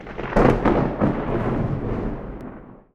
thunder_awp.wav